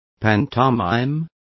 Complete with pronunciation of the translation of pantomime.